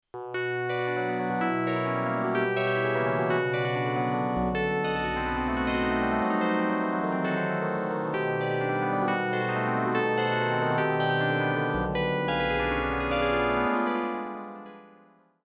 Фортепиано:
Очевидно, что профессиональный и «игровой» FM-синтез разделяет огромная пропасть качества.
12sbpiano.mp3